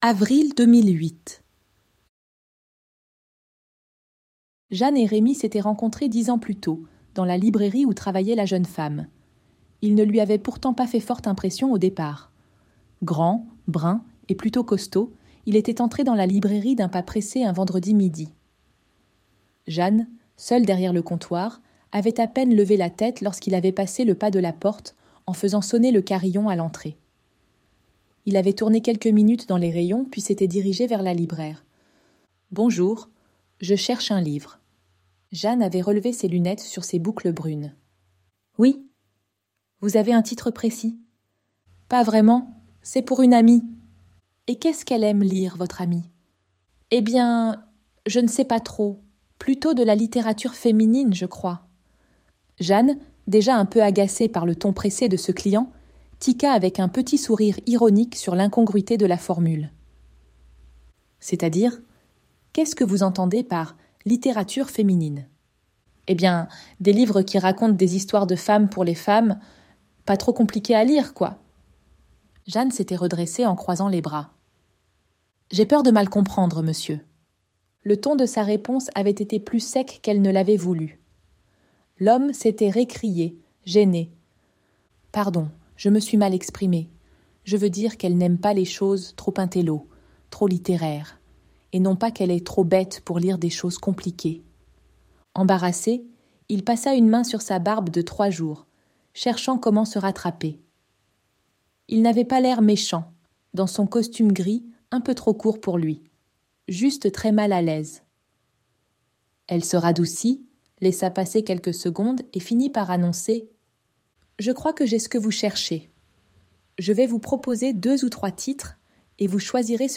Ce livre audio a été enregistré en utilisant une synthèse vocale.